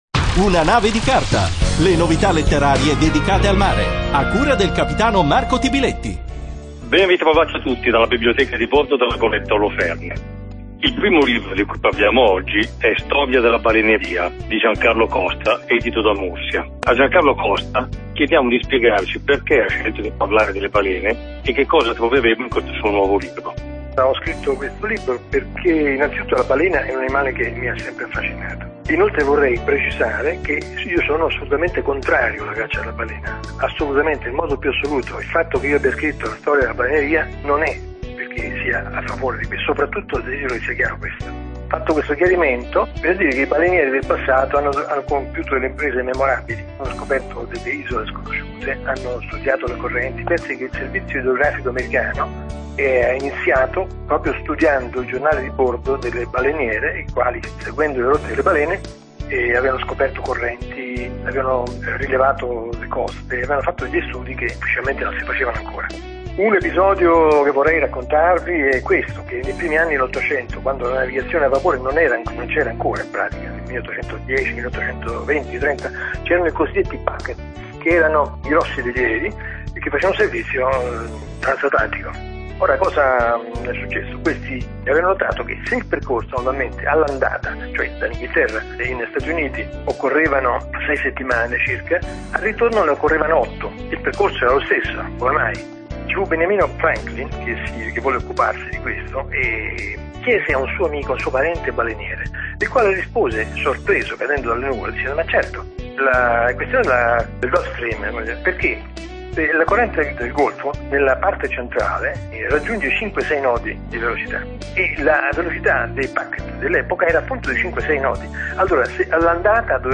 Intervista del cap.